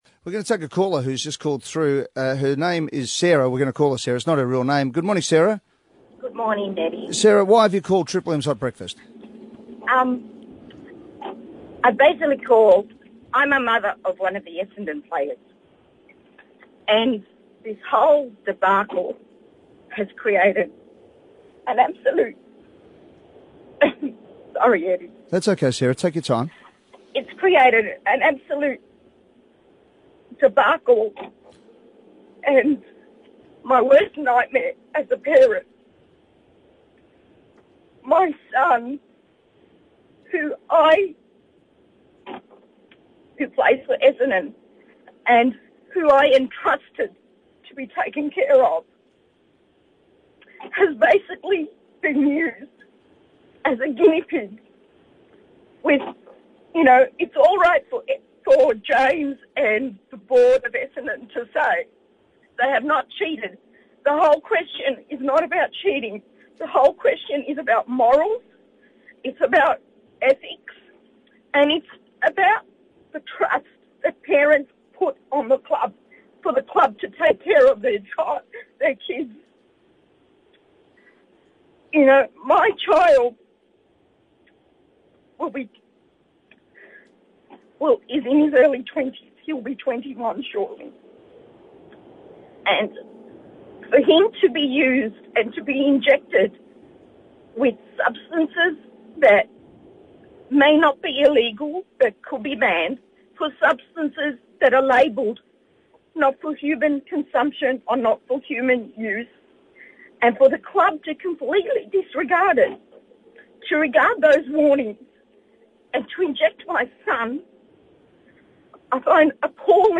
On Thursday morning a mother of an Essendon player called in anonymously to Triple M's Hot Breakfast to vent her frustration with the Essendon supplement saga.